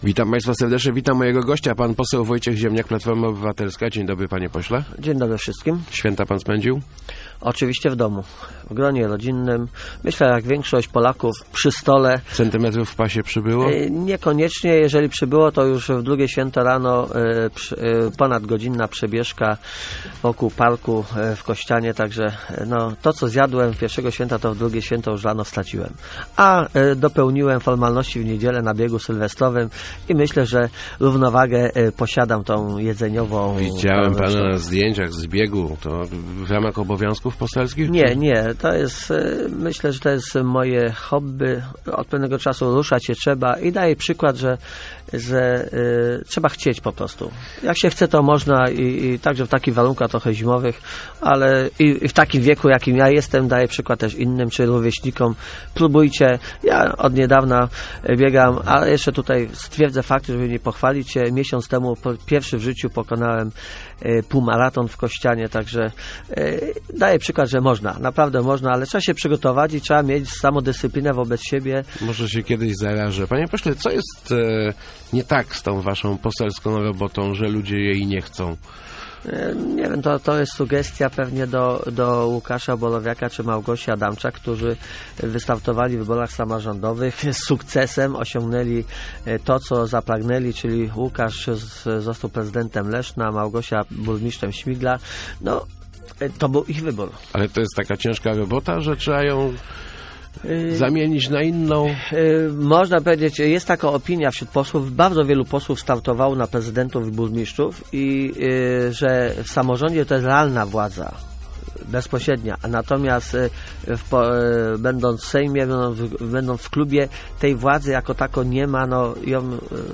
Nie planuję otwarcia biura parlamentarnego w Lesznie - mówił w Rozmowach Elki poseł Wojciech Ziemniak. To obecnie jedyny poseł PO z ziemi leszczyńskiej, po samorządowych sukcesach Małgorzaty Adamczak i Łukasza Borowiaka.